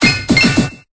Cri d'Archéomire dans Pokémon Épée et Bouclier.